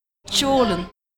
prononciation
tjolen-pron.mp3